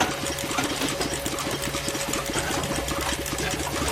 machine_idle_2star_01.ogg